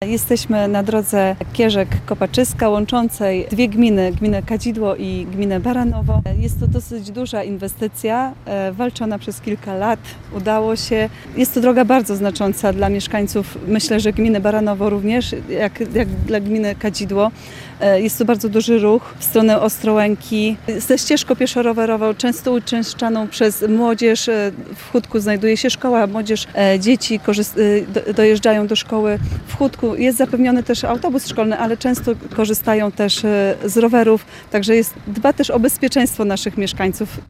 Wójt Gminy Kadzidło, Anna Śniadach podkreśla, że to ważna inwestycja dla mieszkańców regionu: